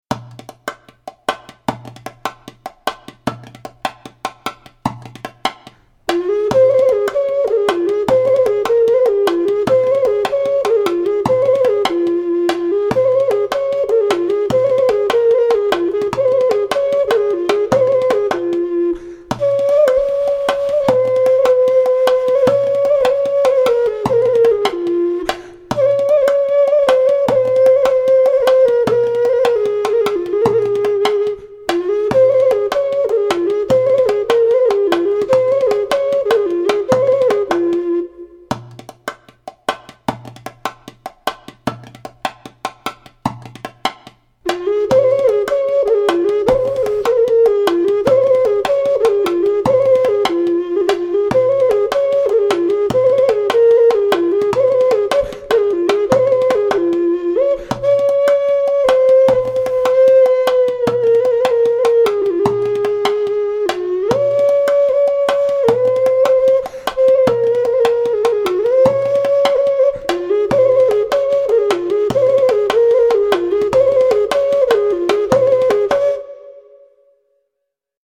Etno-World ocarina, djem'bukah